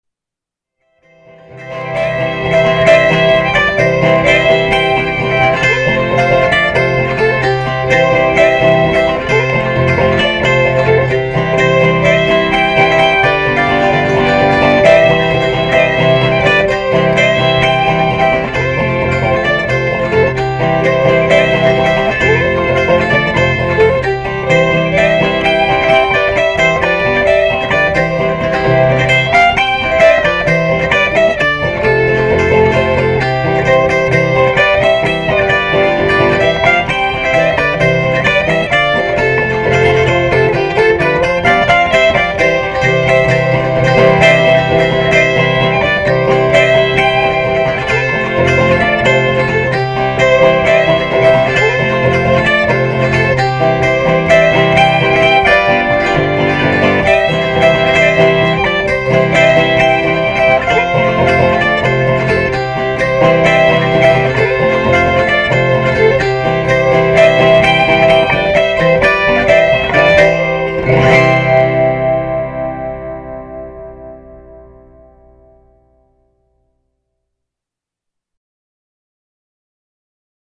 Zimmermann No. 2 7/8 autoharp, fiddle
Next up is an old sentimental song from Charlie Poole, played on the Dolgeville No. 2 7/8 autoharp and the fiddle.